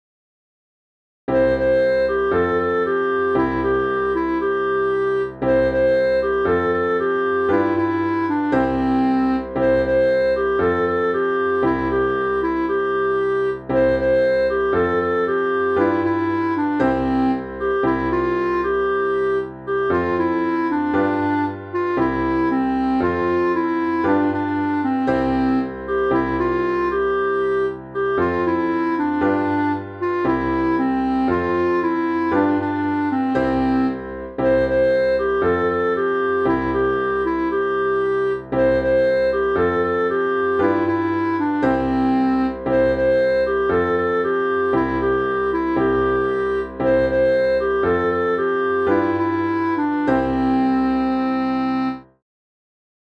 Funga Alafia (Cançó tradicional de Nigèria i oest d’Àfrica)
Interpretació musical de la cançó tradicional de Nigèria i oest d'Àfrica